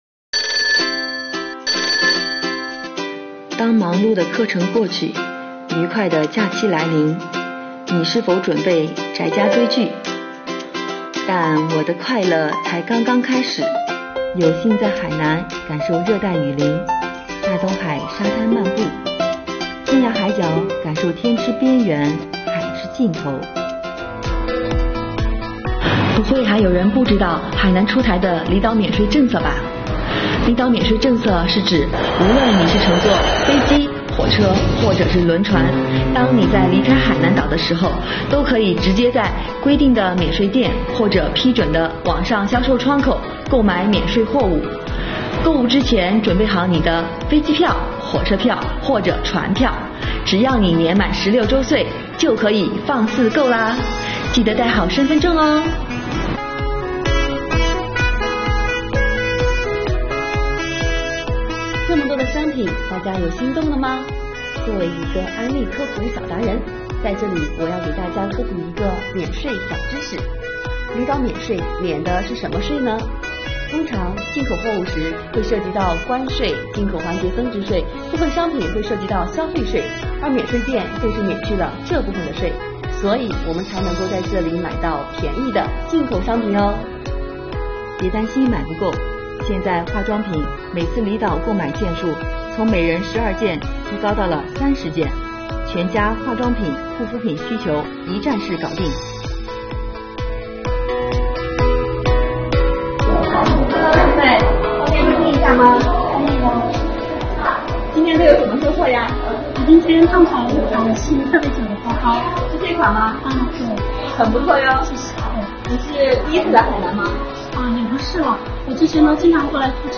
下面这位小姐姐替你去踩点了